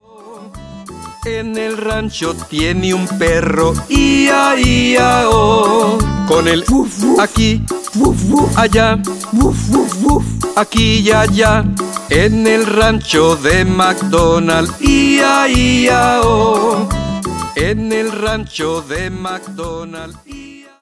versión en español
Canción